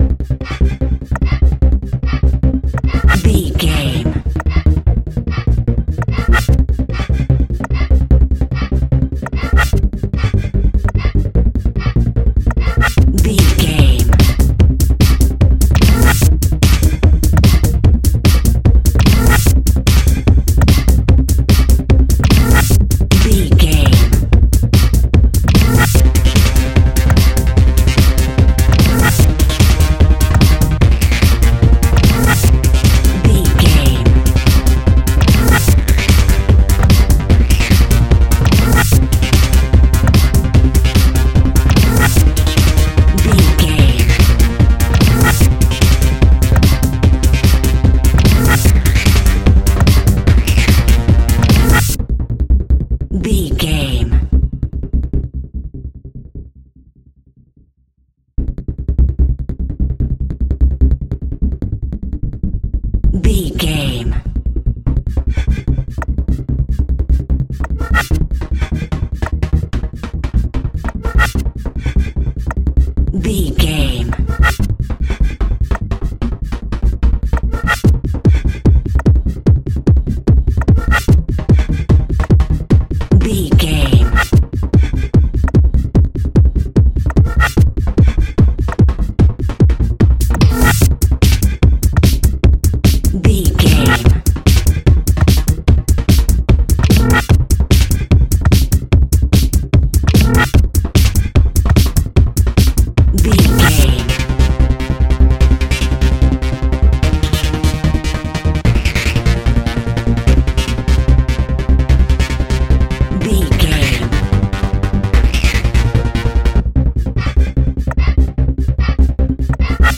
Epic / Action
Fast paced
Aeolian/Minor
Fast
groovy
uplifting
driving
energetic
repetitive
piano
drum machine
synthesiser
acid trance
uptempo
synth leads
synth bass